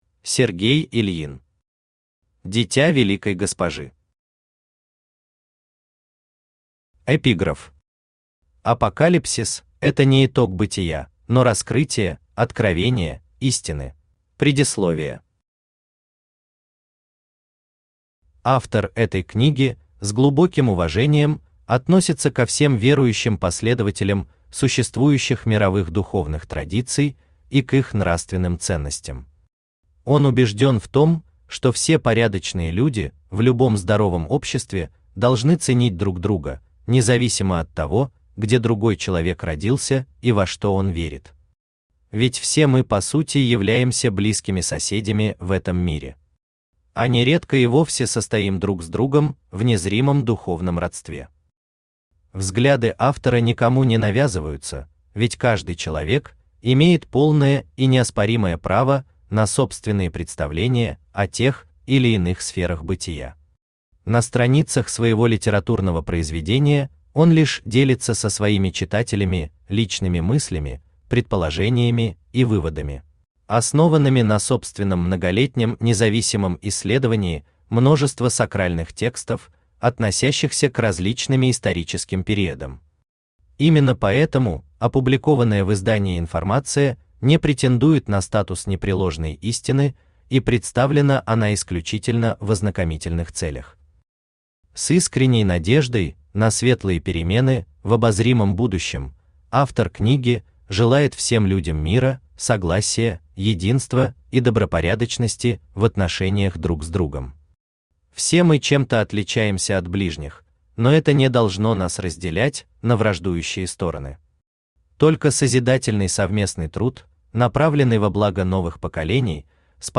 Аудиокнига «Дитя» Великой Госпожи | Библиотека аудиокниг
Aудиокнига «Дитя» Великой Госпожи Автор Сергей Ильин Читает аудиокнигу Авточтец ЛитРес.